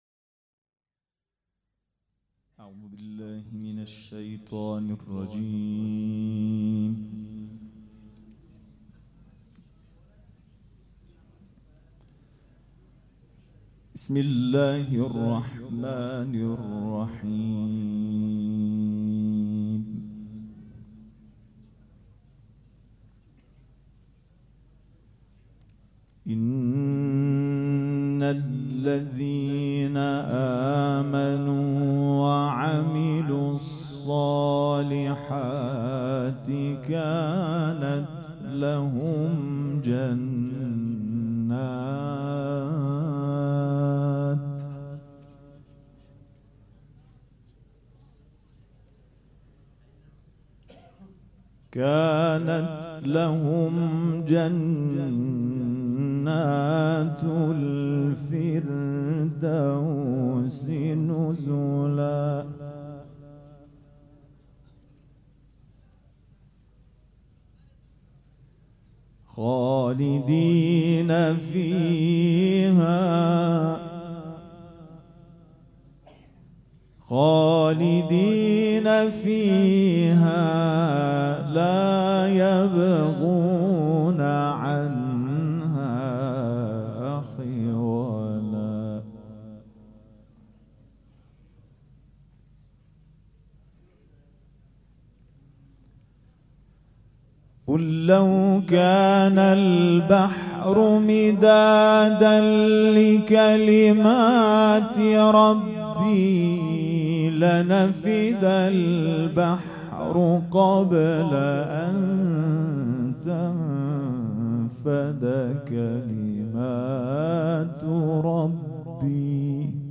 دانلود تصویر قرآن کریم favorite قرائت قرآن